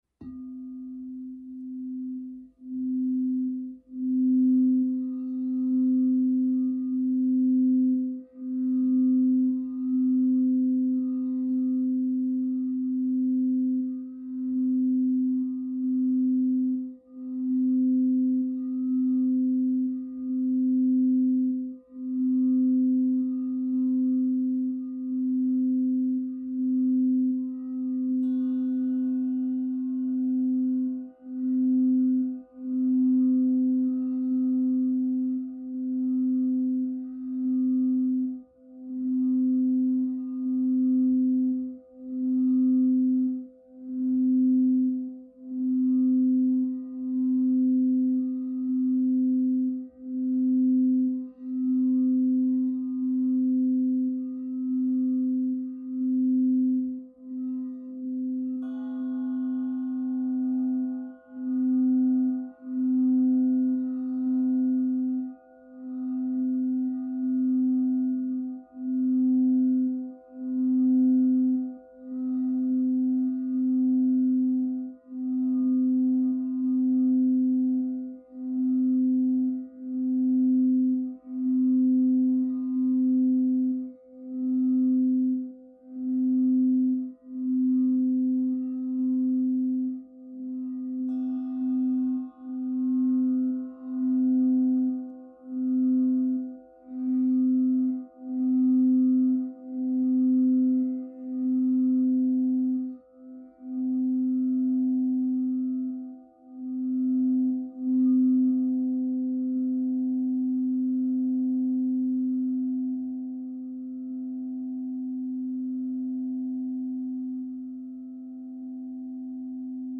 Sound healing sleep sound effects free download